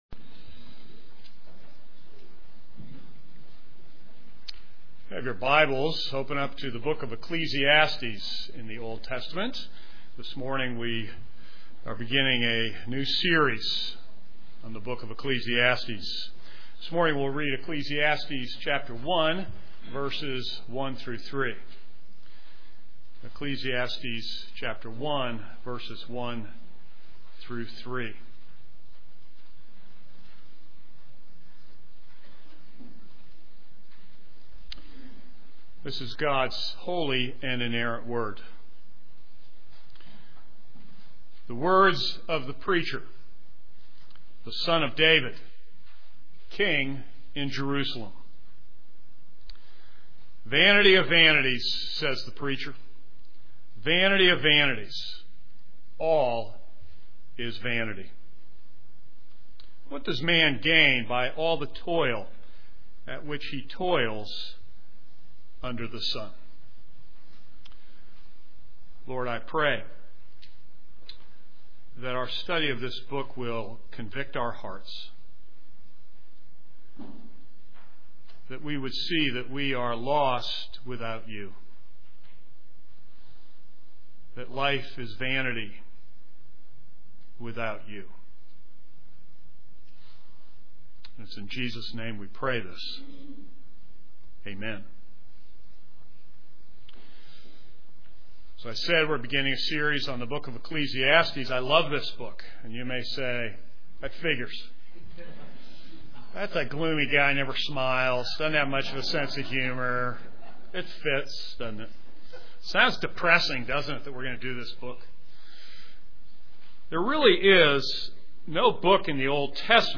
This is a sermon on Ecclesiastes 1:1-3.